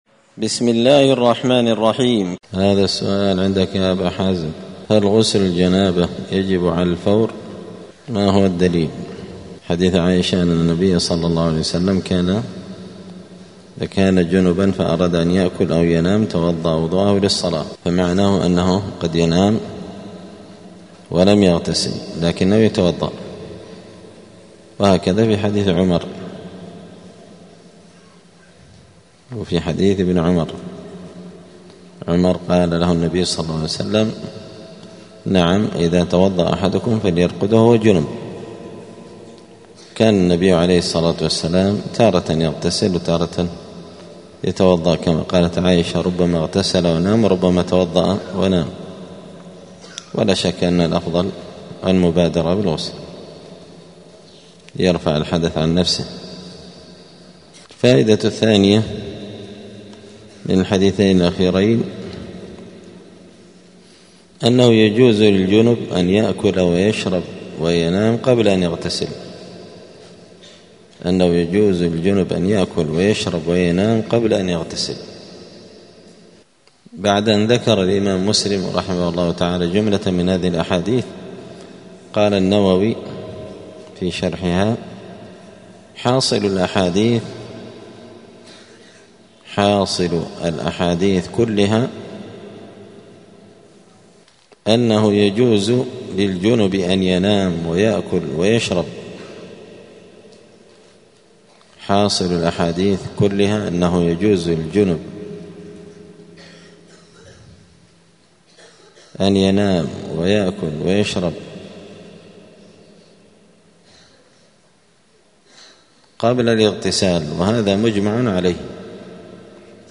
دار الحديث السلفية بمسجد الفرقان قشن المهرة اليمن
*الدرس الخامس والسبعون [75] {باب مايوجب الغسل، حكم الوضوء للجنب إذا أراد أن يأكل أو ينام}*